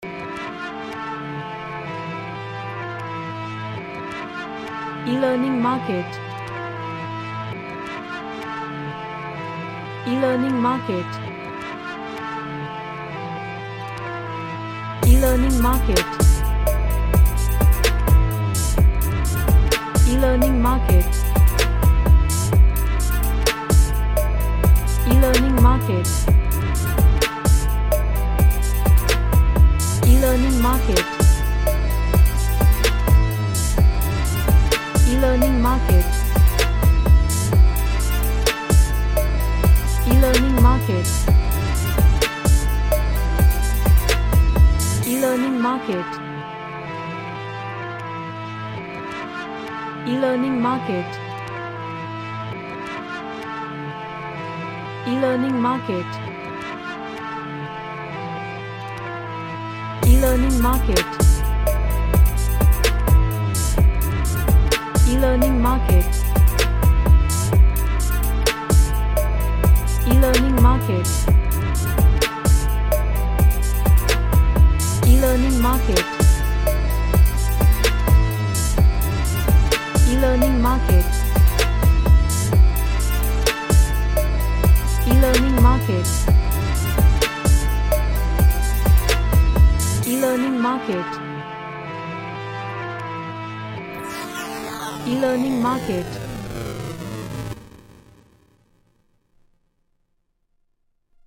Sad / Nostalgic